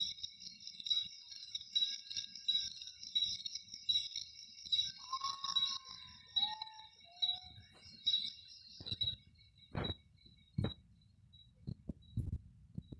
Urutaú Común (Nyctibius griseus)
Nombre en inglés: Common Potoo
Fase de la vida: Adulto
Localidad o área protegida: Río Ceballos
Condición: Silvestre
Certeza: Vocalización Grabada